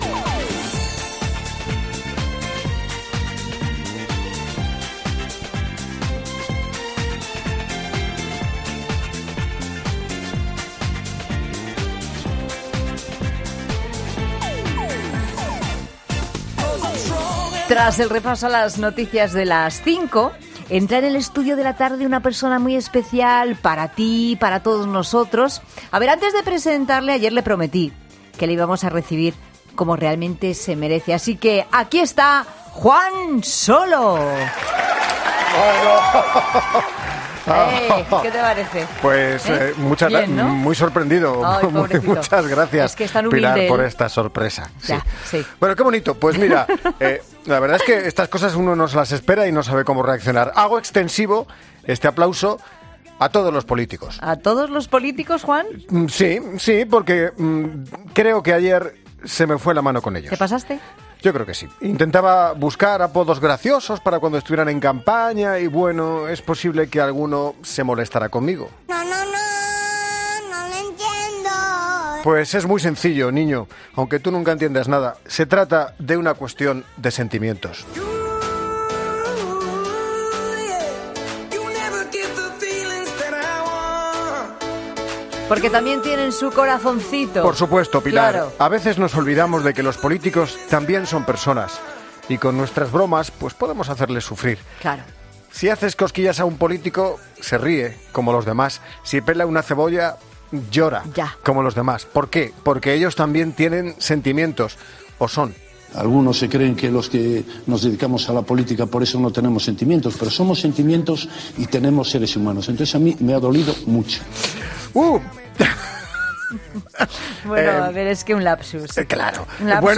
'La Tarde', un programa presentado por Pilar Cisneros y Fernando de Haro, es un magazine de tarde que se emite en COPE, de lunes a viernes, de 15 a 19 horas.